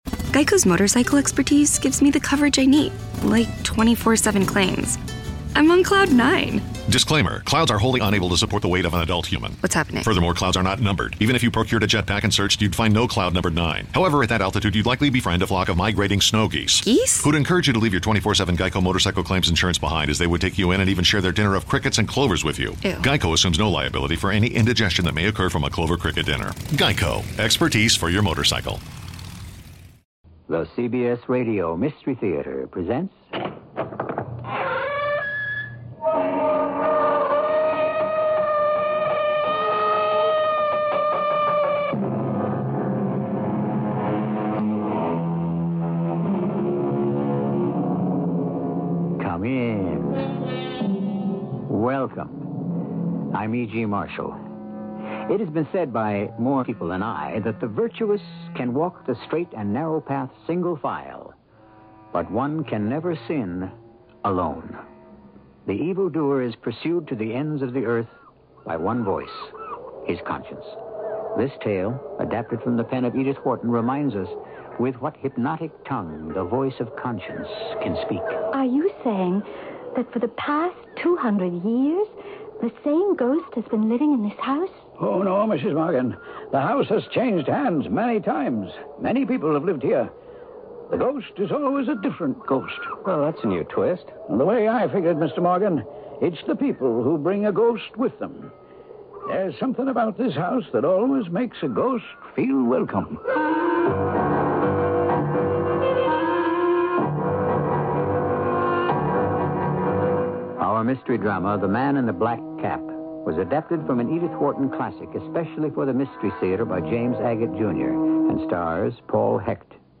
CBS Radio Mystery Theater (a.k.a. Radio Mystery Theater and Mystery Theater, sometimes abbreviated as CBSRMT) was a radio drama series created by Himan Brown that was broadcast on CBS Radio Network affiliates from 1974 to 1982